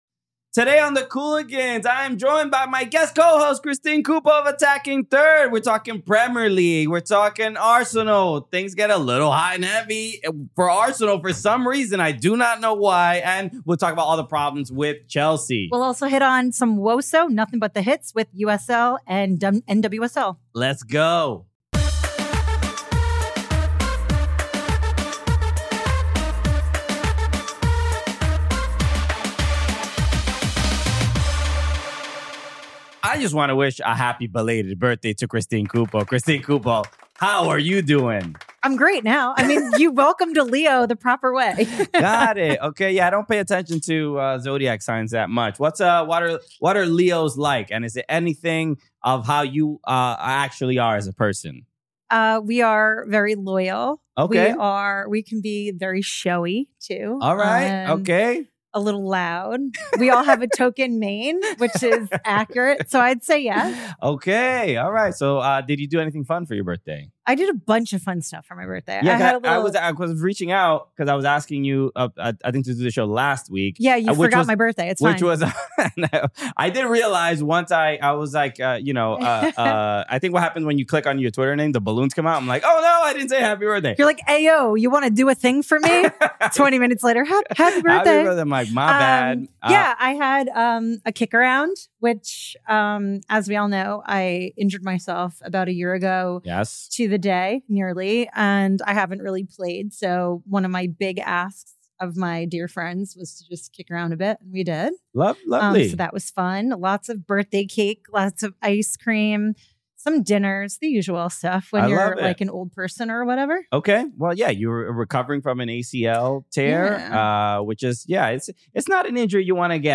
a wonderful interview